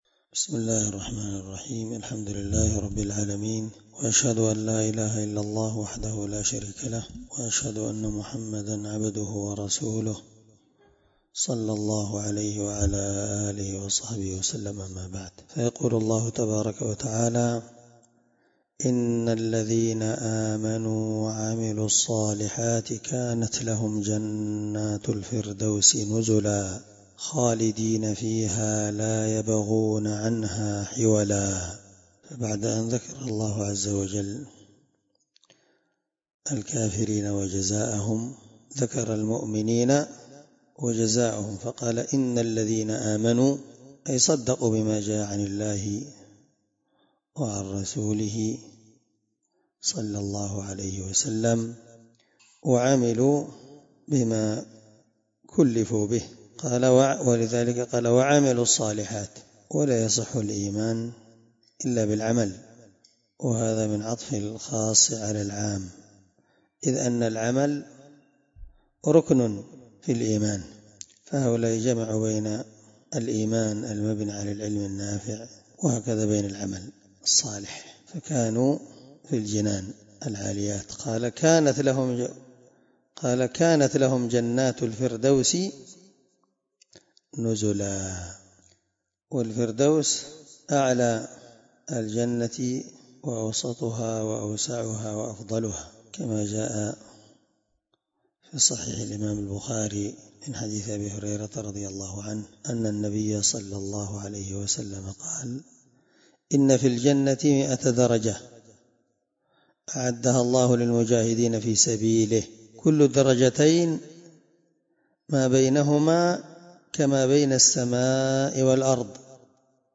الدرس32 تفسير آية (107-108) من سورة الكهف